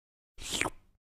PLAY Slurp Meme Sound Effect for Soundboard
Slurp-Sound-Effect.mp3